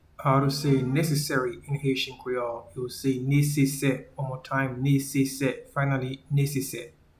Pronunciation:
Necessary-in-Haitian-Creole-Nesese.mp3